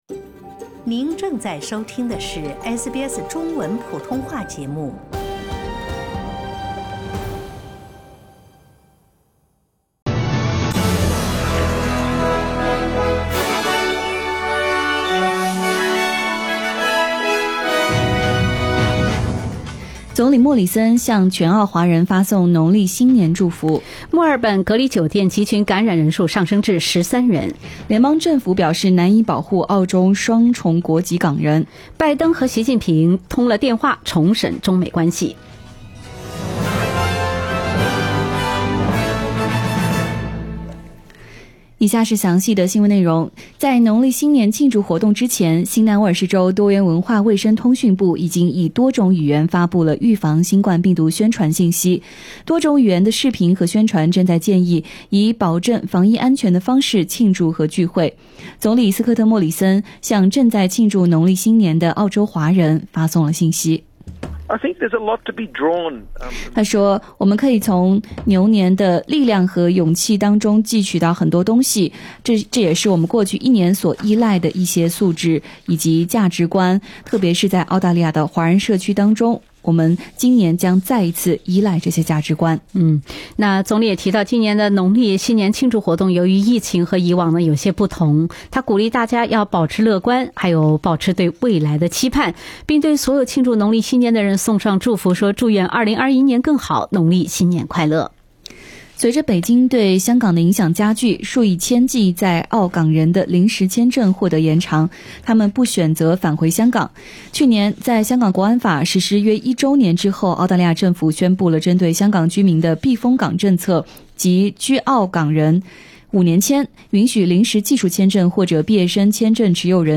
SBS早新聞（2月12日）